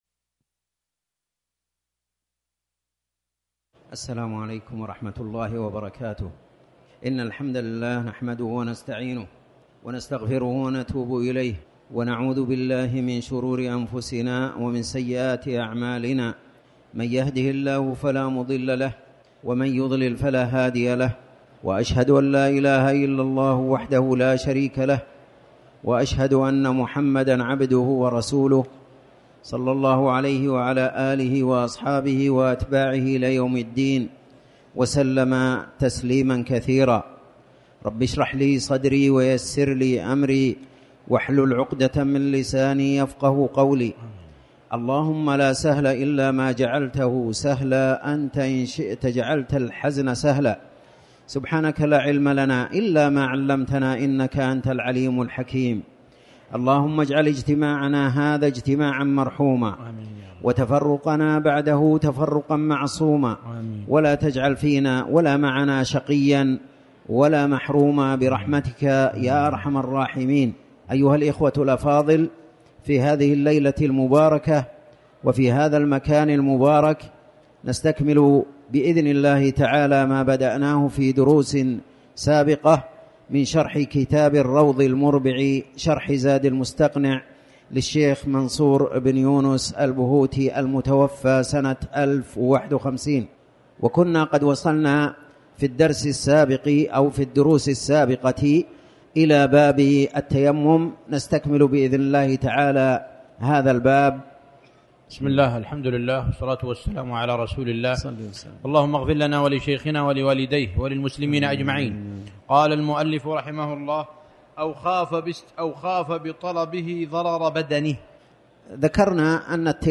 تاريخ النشر ١٥ جمادى الأولى ١٤٤٠ هـ المكان: المسجد الحرام الشيخ